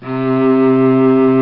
Cello Sound Effect
Download a high-quality cello sound effect.
cello.mp3